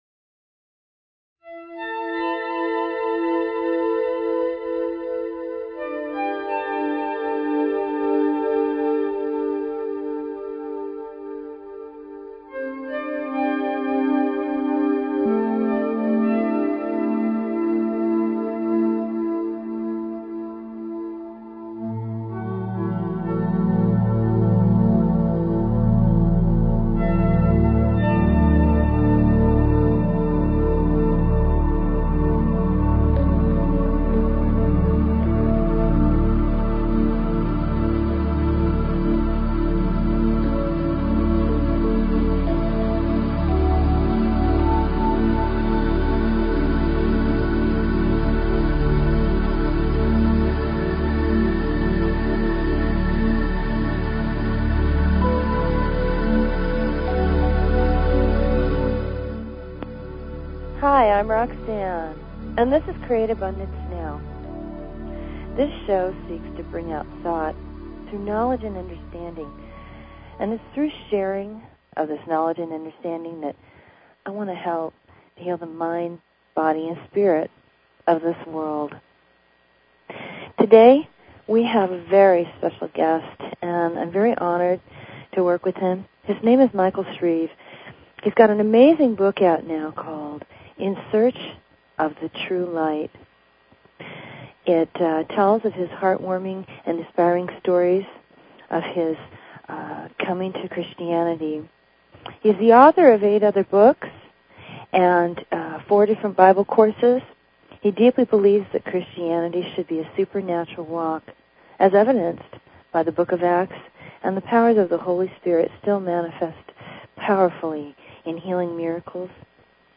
Talk Show Episode, Audio Podcast, Create_Abundance_Now and Courtesy of BBS Radio on , show guests , about , categorized as
Show Headline Create_Abundance_Now Show Sub Headline Courtesy of BBS Radio Check This interview out.